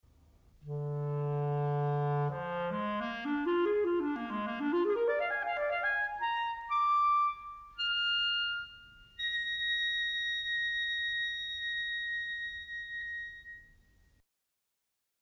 Il clarinetto possiede un timbro dolce e mellifluo ed è uno strumento capace di notevoli agilità, particolarmente nell'esecuzione di scale e arpeggi.
suono del clarinetto
clarinetto_suono.mp3